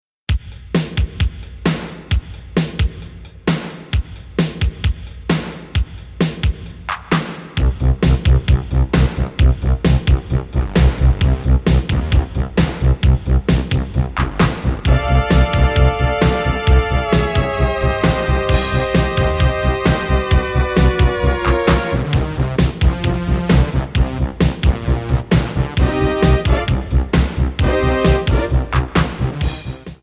Dbm
MPEG 1 Layer 3 (Stereo)
Backing track Karaoke
Pop, Disco, 1980s